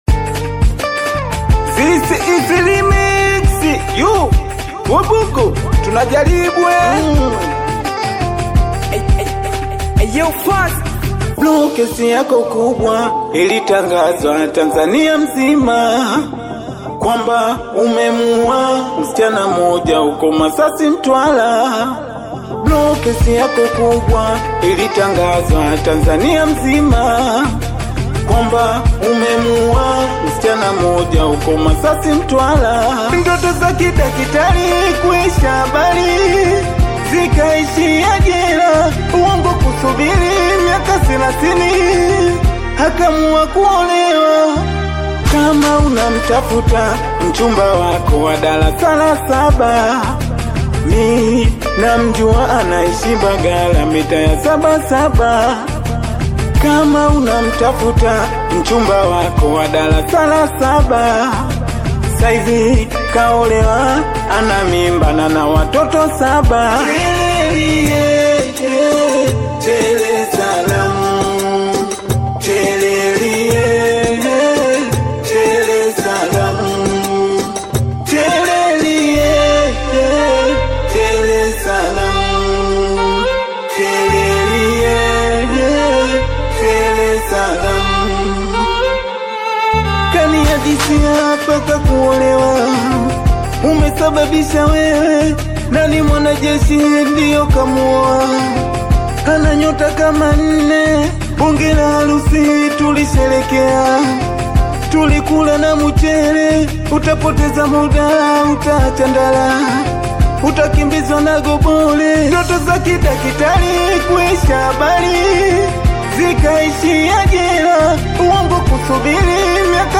AudioSingeli
high-energy Singeli track